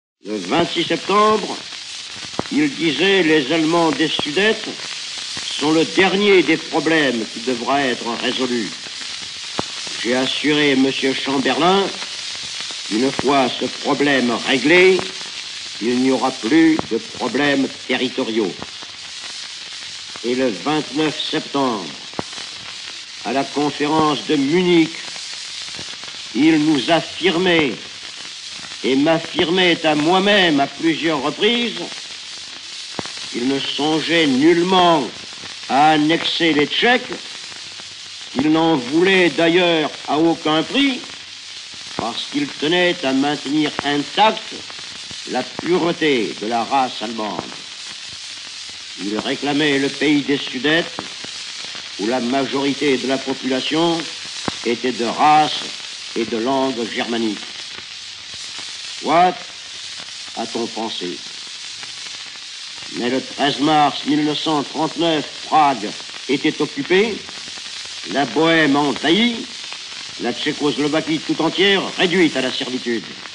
De Gaulle (appels des 22 et 28 juin 1940) Hitler (1er septembre 1939) au sujet de la Pologne Roosevelt (1942) Mussolini (14 mai 1939) Daladier (21 septembre 1939) Eisenhower (septembre 1944) Pétain (17 juin 1940) Goebbels (15 mars 1939) Elisabeth d'Angleterre (15 juin 1940) Staline (novembre 1944) Enregistrement original 10 pistes Durée totale : 19 minutes